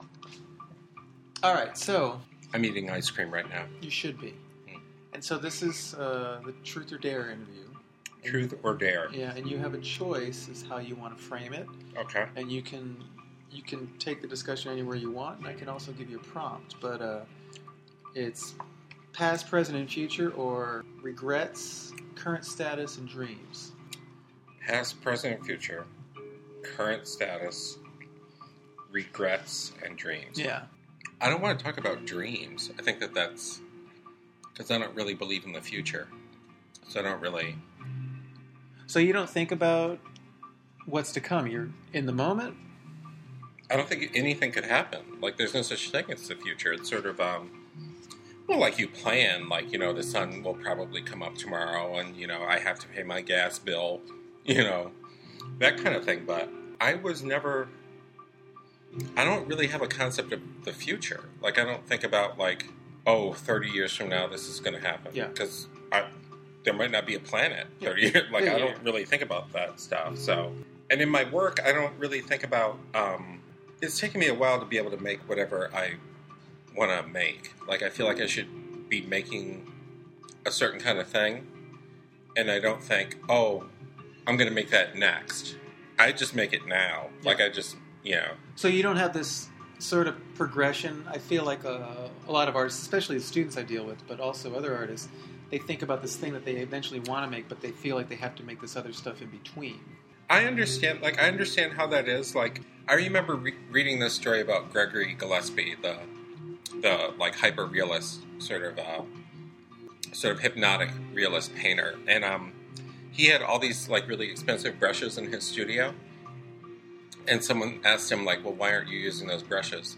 Contributors can answer Truth, which directly poses; Past? Present? and Future? or Dare, which bluntly asks; Regrets? Status? and Dreams? The interviews aspire to open up candid platforms for cultural thinkers to contemplate and respond to a broad range of complex, imaginative, personal, controversial, and/or analytical, etc. forces of time and space that correlate with their own “Truth or Dare” experiences in a myriad of contemporary art practices.